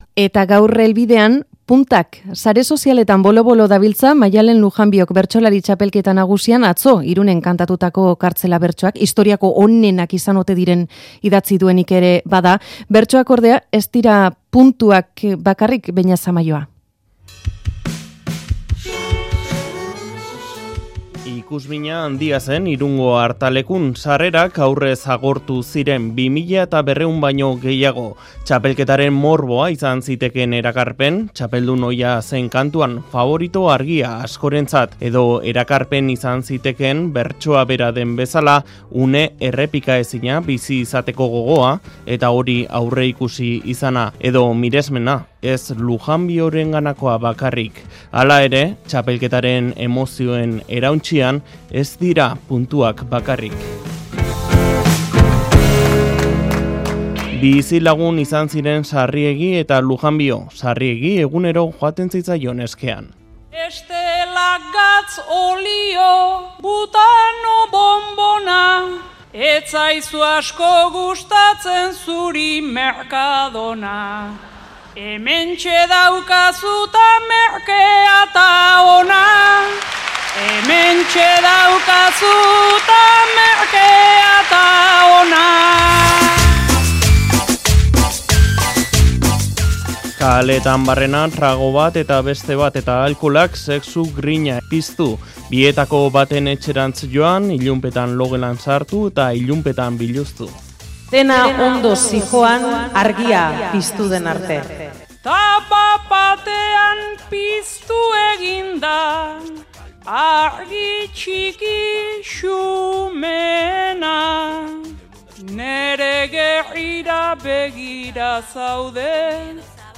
Bertsoak.